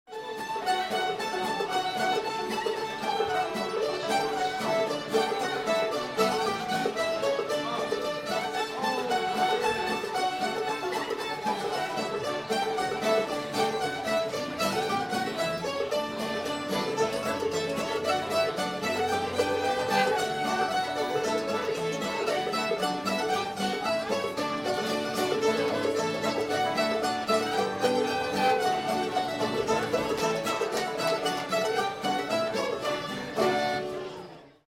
Tunes from 2011-02-10
rock the cradle joe [D]